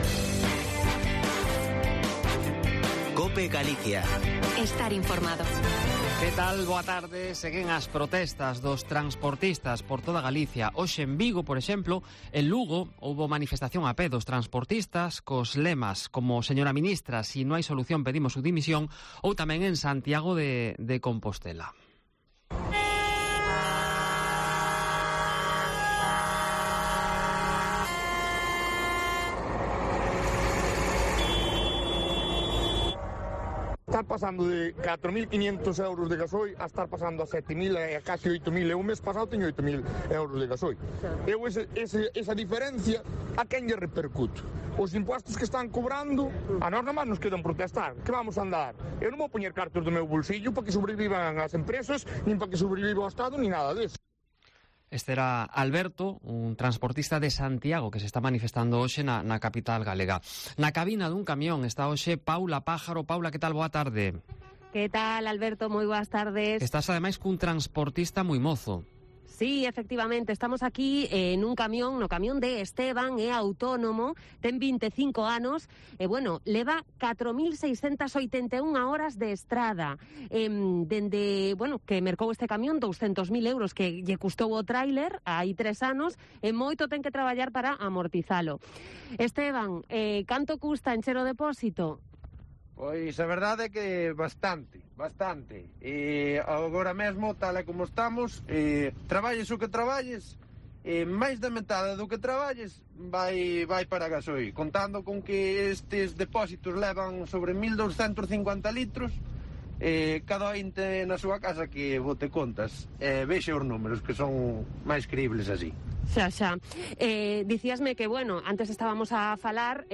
Herrera en Cope Galicia 24/03/2022 de 12.20 a 1230h. Desde la cabina de un camión